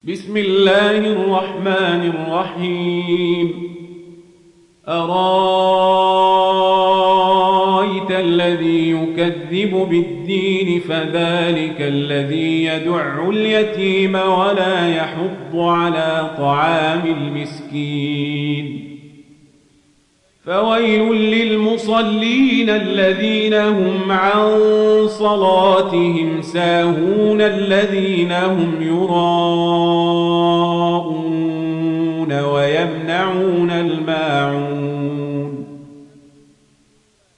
دانلود سوره الماعون mp3 عمر القزابري روایت ورش از نافع, قرآن را دانلود کنید و گوش کن mp3 ، لینک مستقیم کامل